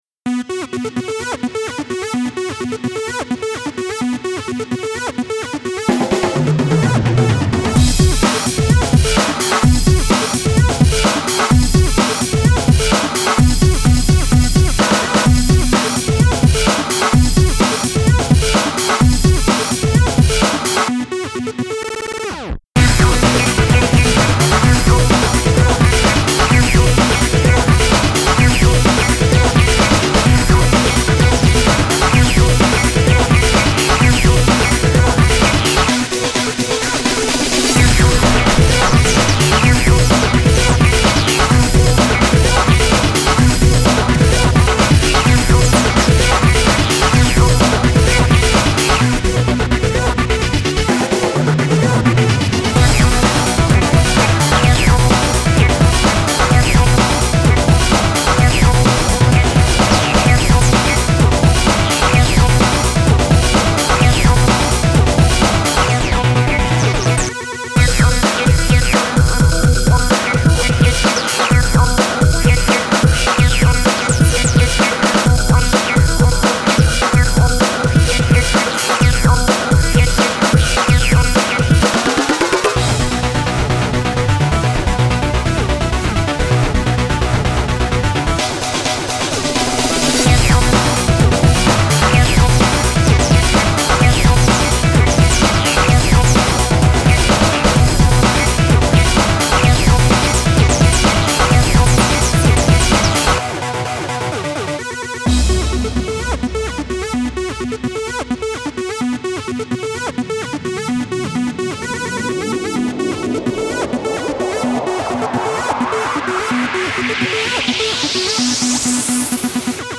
Here’s something I knocked up real quick whilst riffing live in Ableton Live playing with a softsynth via the controller keyboard, triggering/stopping drums/stutter effects on the computer keyboard. No real melodies per se. Just programmed arps, reworked live drums/drumloops.